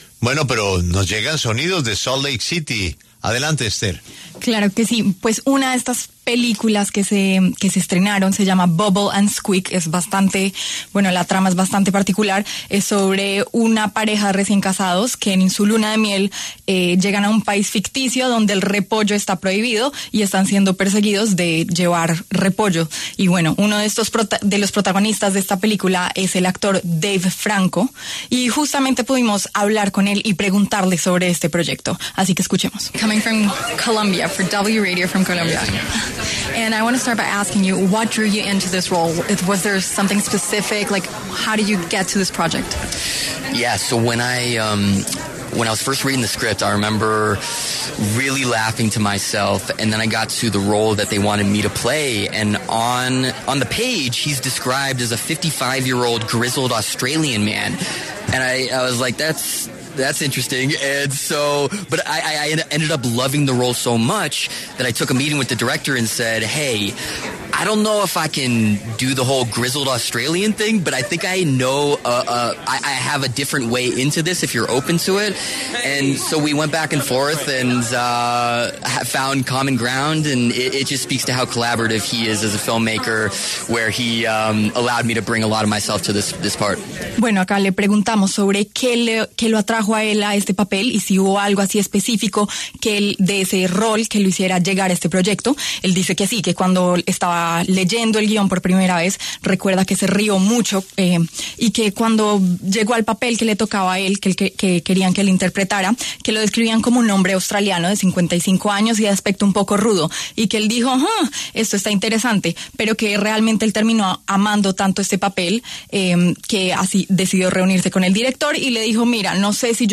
Dave Franco, protagonista de Bubble & Squeak, pasó por los micrófonos de La W desde el Festival de Sundance.